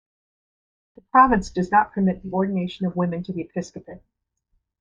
Pronounced as (IPA) /ɪˈpɪskəpət/